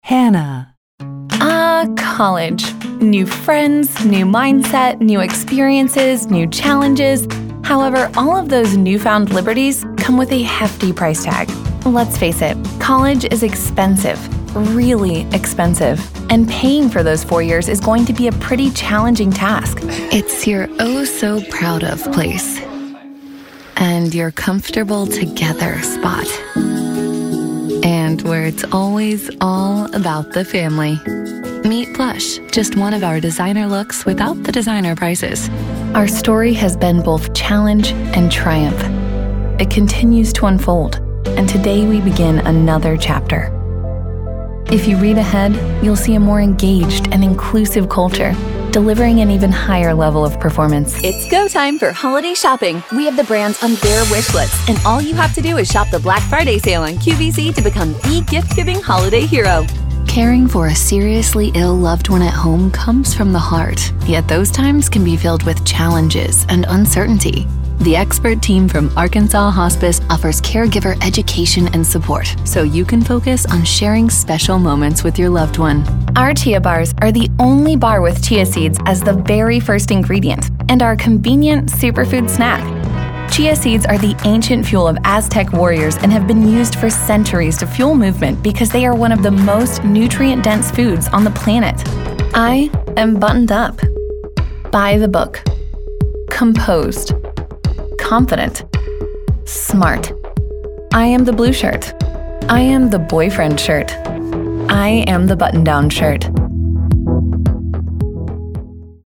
Showcase Demo